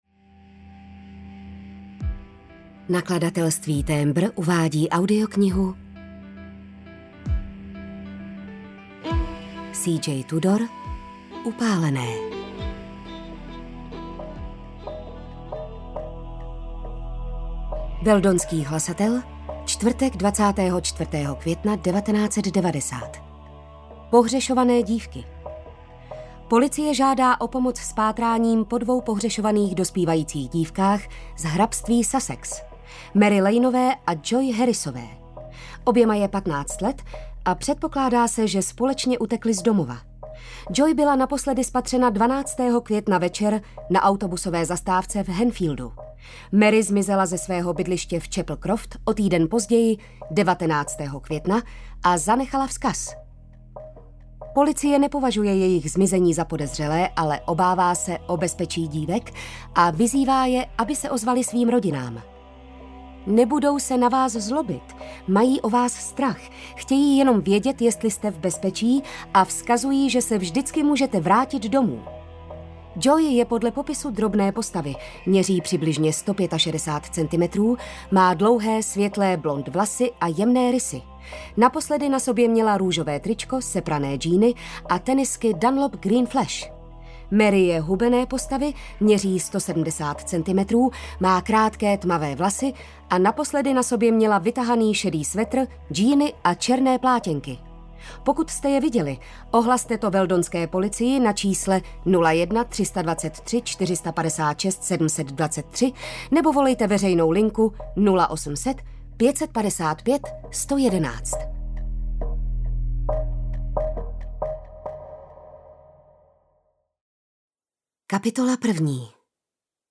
Upálené audiokniha
Ukázka z knihy